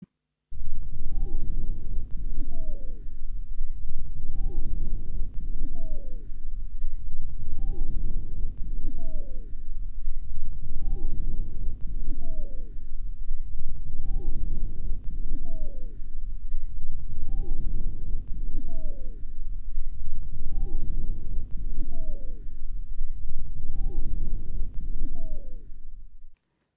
Звуки отдышки
Хрипящие звуки при ХОБЛ